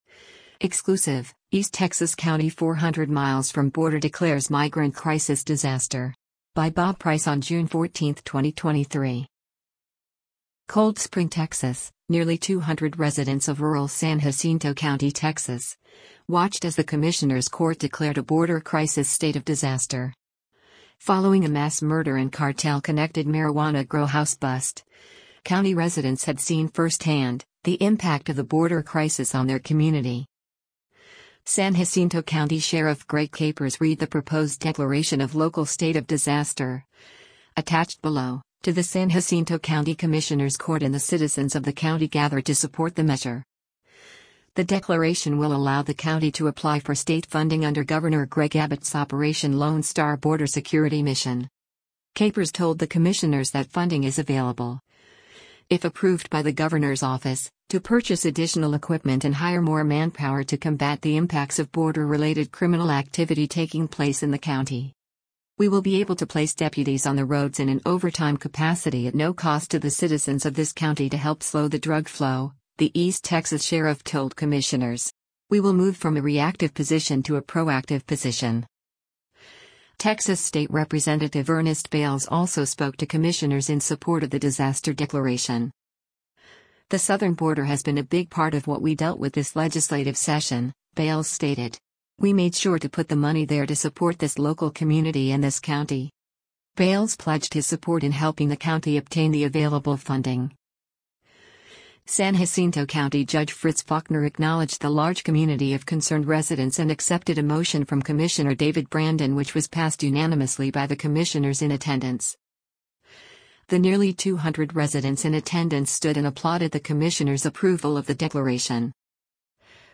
San Jacinto County Commissioners hear from residents in support of a border-crisis disaste